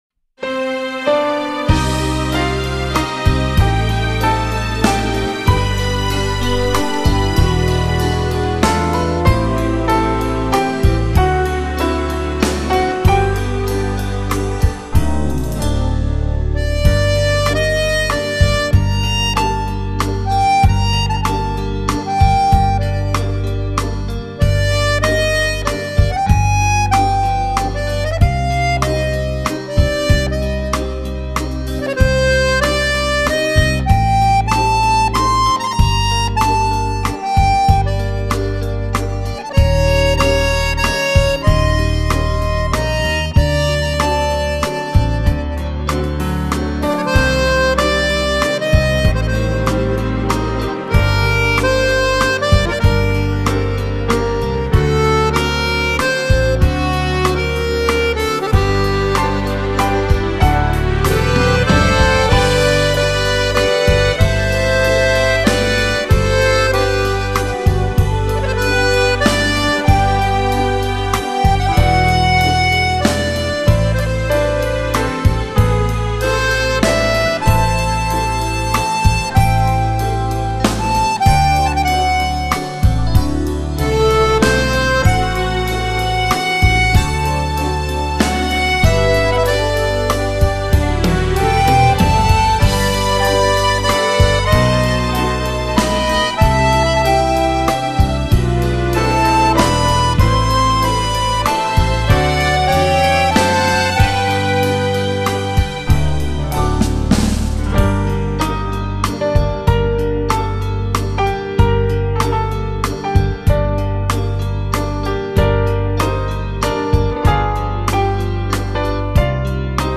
Genere: Valzer Lento